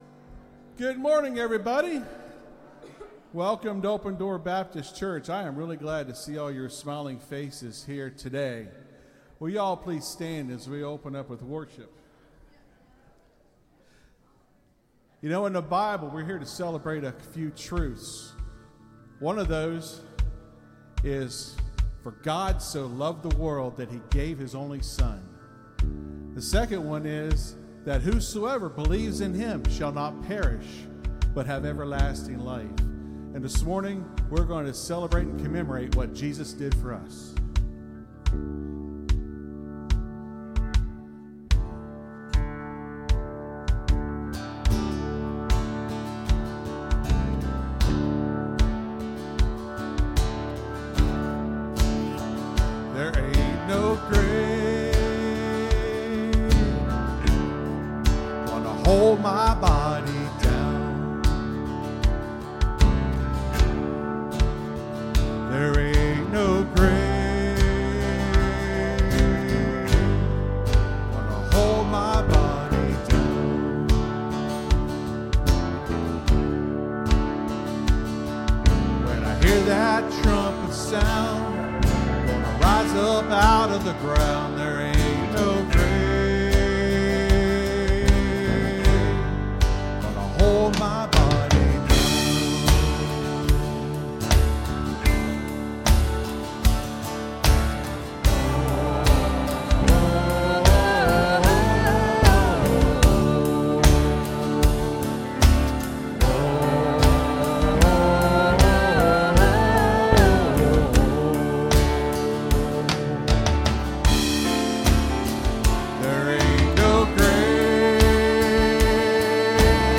(Sermon starts at 27:55 in the recording).